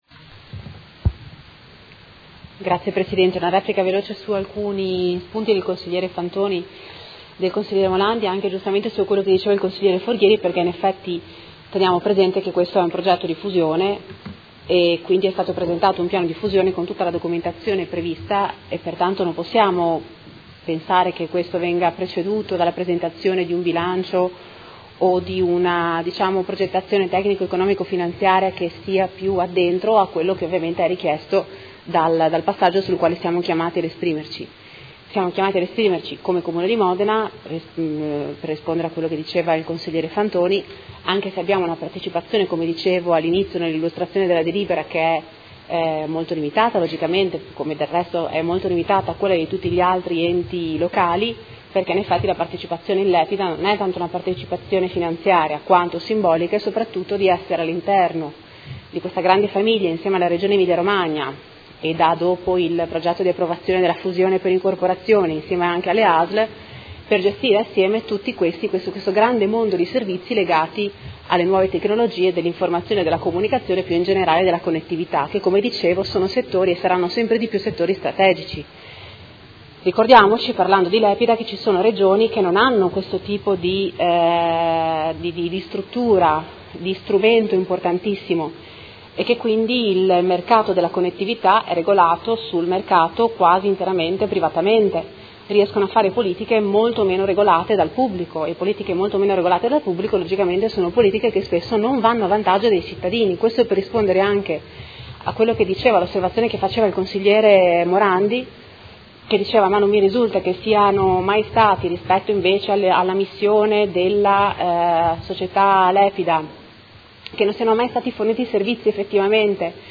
Seduta del 11/10/2018. Replica al dibattito su proposta di deliberazione: Approvazione del Progetto di Fusione per incorporazione di CUP2000 Soc. Cons. P.A. in Lepida S.p.A con contestuale trasformazione eterogenea ex art. 2500-Septies C.C. della società incorporante in società consortile per azioni